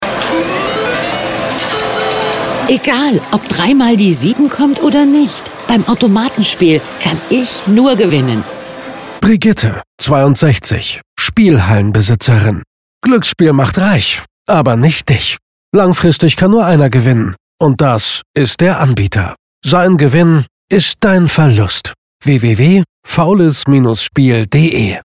• Radiospot Spielhalle